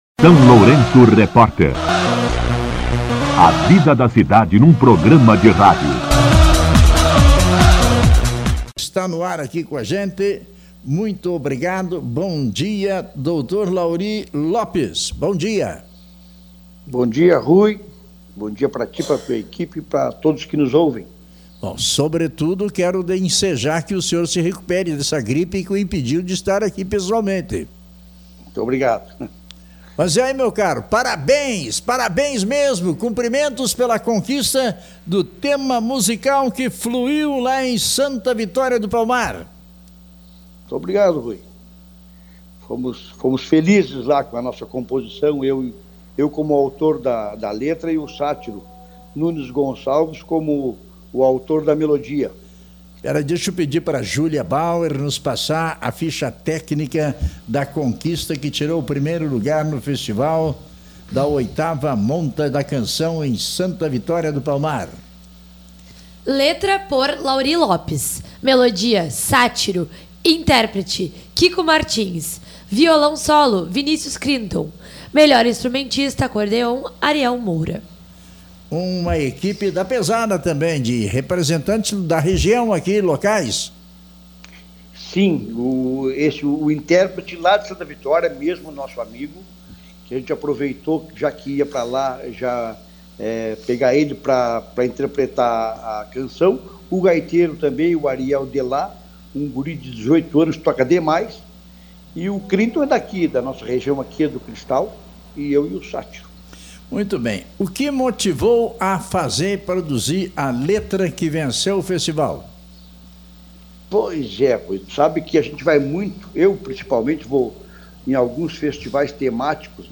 Violão Solo
Acordeon